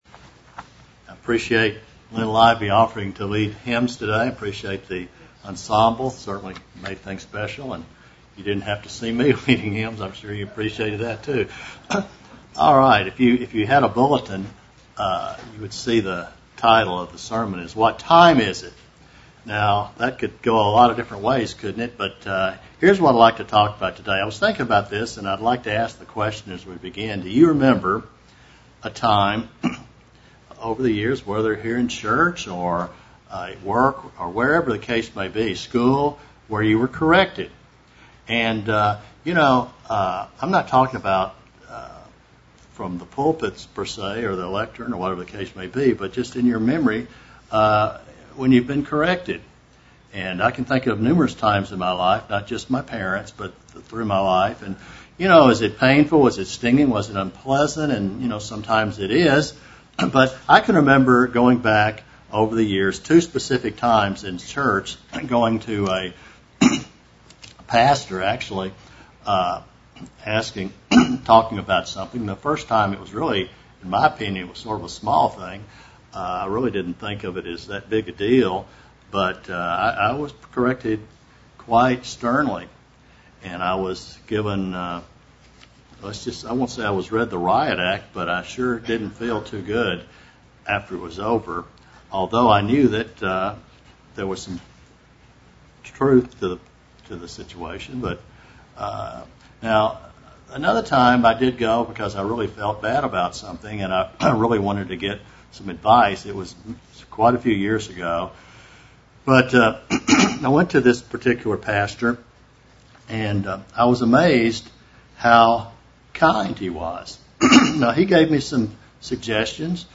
What should we be specifically asking of God? This sermon will deal with what we should be doing with our time at this point in time.